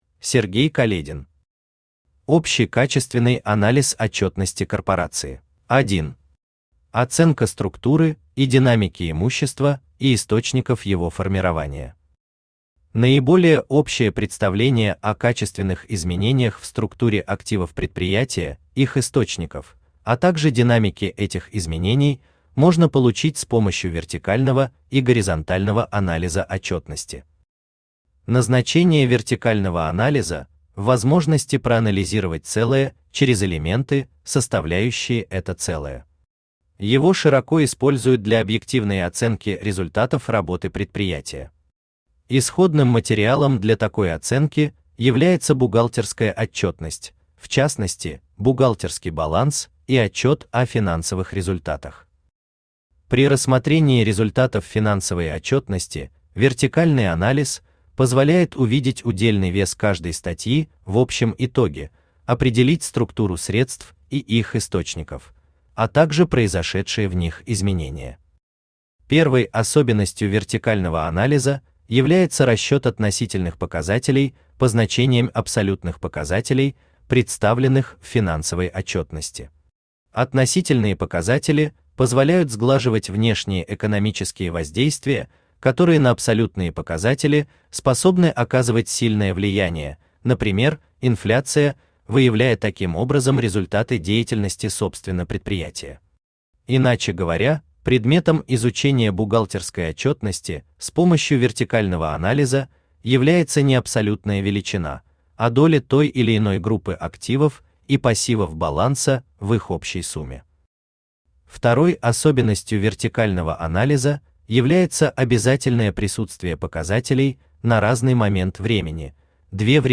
Аудиокнига Общий качественный анализ отчётности корпорации | Библиотека аудиокниг
Aудиокнига Общий качественный анализ отчётности корпорации Автор Сергей Каледин Читает аудиокнигу Авточтец ЛитРес.